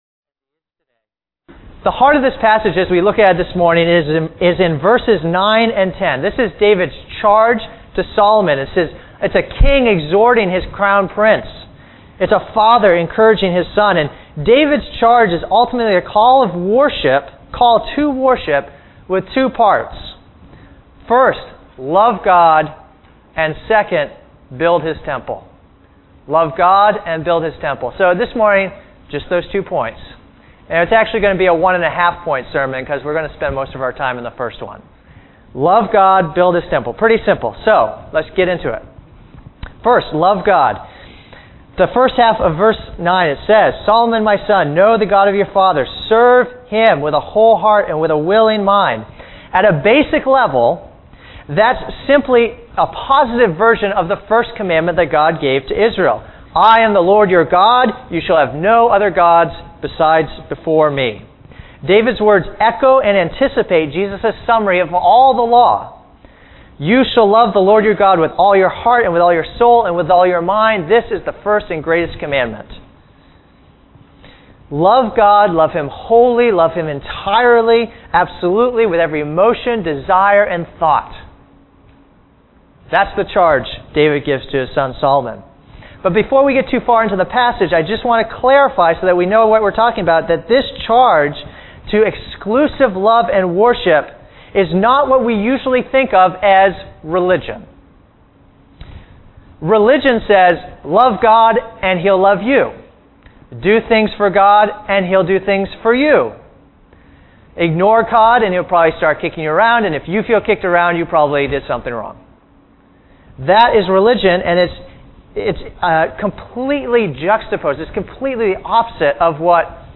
A message from the series "David."